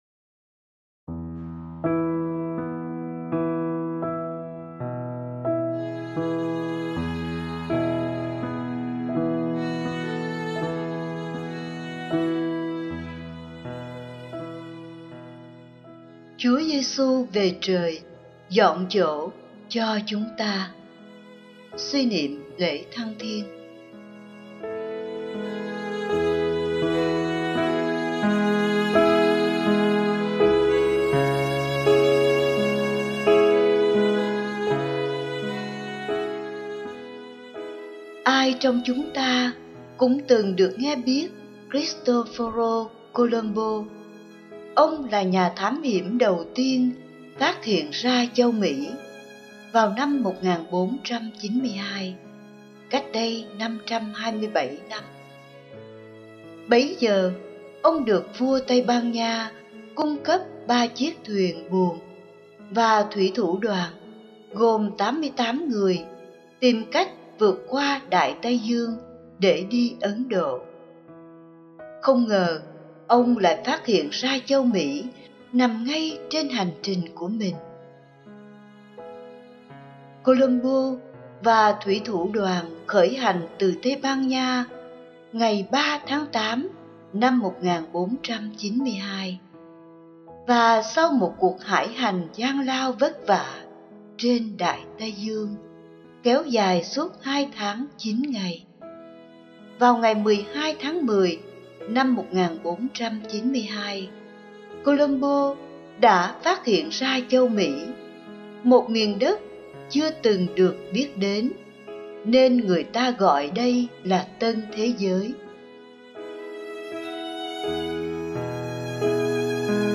(Suy niệm Lễ Thăng thiên)